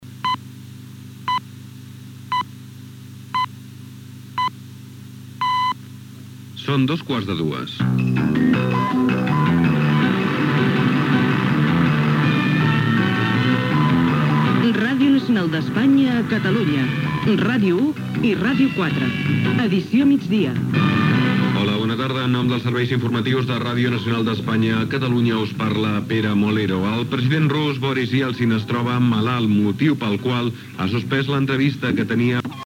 Hora, careta del programa i primer titular.
Informatiu
FM